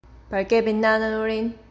click to listen to the pronunciation)